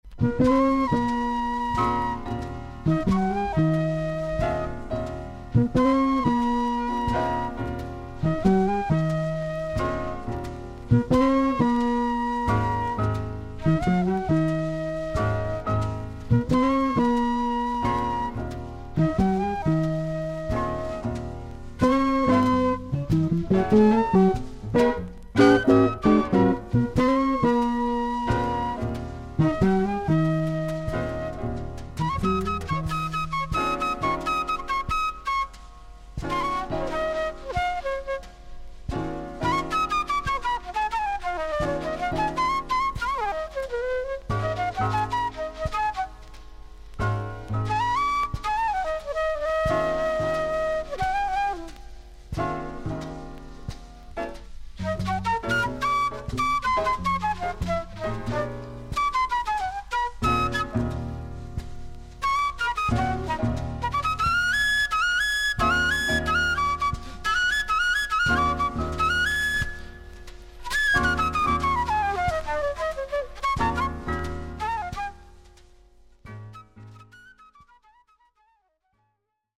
少々軽いパチノイズの箇所あり。少々サーフィス・ノイズあり。クリアな音です。
ジャズ・ギタリスト。
例のオクターブ奏法はもちろん、4曲でバリトン・ギターもプレイしています。